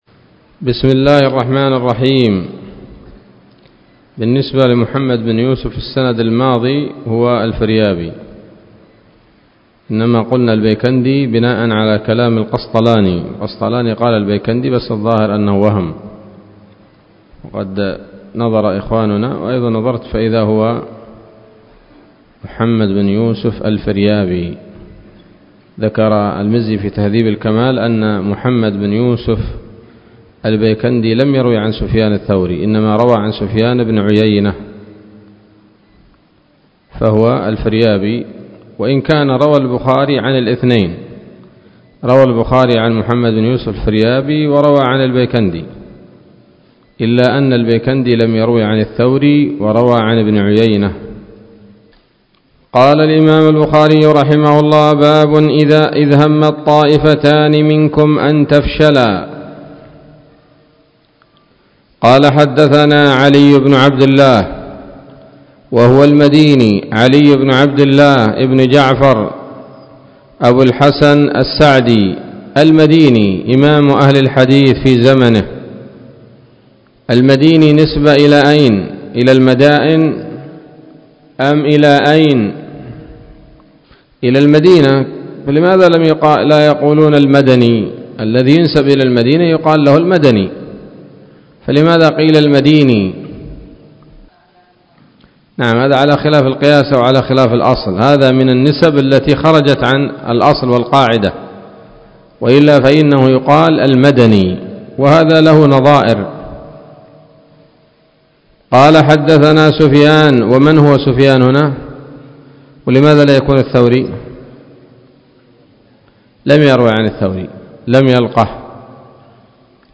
الدرس الثاني والخمسون من كتاب التفسير من صحيح الإمام البخاري